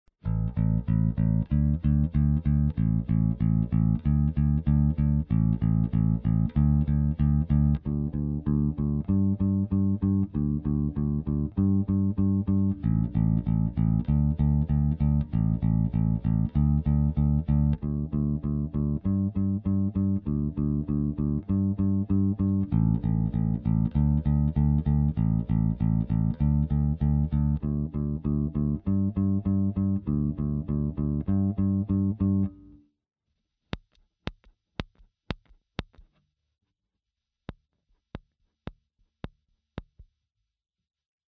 Nordstrand Big Split D, G und C Saite klingen dumpf - Pickup defekt?
Gespielt wird zum Vergleich abwechselnd die A-Saite (hell und lauter) und D Saite (dumpf und leiser) und danach wird mit einem nichtmagnetischen kleinen Inbusschlüssel auf die Pole Pieces zuerst der A-Saite (hell und lauter) und danach der D-Saite (dumpf und leiser) geklopft. Kann es sein, dass der Pickup defekt, bzw. ein Produktionsfehler ist?